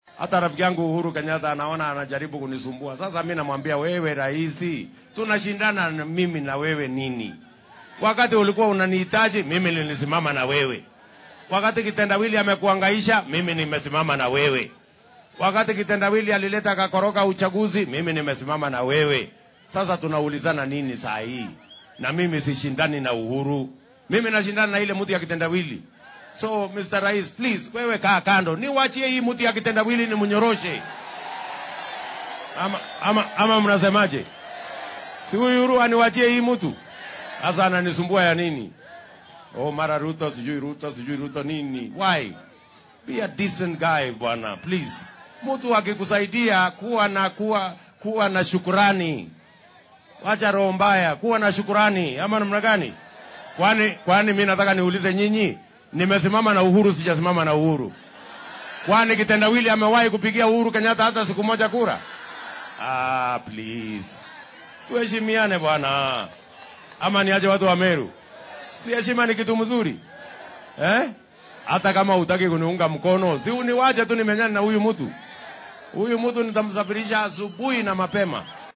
Madaxweyne ku xigeenka dalka ahna musharraxa madaxweyne ee xisbiga UDA ahna madaxweyne ku xigeenka dalka William Ruto oo maanta isku soo bax siyaasadeed ku qabtay ismaamulka Meru ayaa madaxweynaha dalka Uhuru Kenyatta ugu baaqay inuu soo afjaro dhaliisha uu u jeedinaya . Waxaa uu ku boorriyay in haddii uunan soo dhisayn uu fursad u siiyo la tartamidda Raila Odinga isagoo aan soo faragelin. Ruto ayaa hoggaamiyaha dalka ku eedeynaya inuunan ugu abaal gudin sidii uu u taageeray.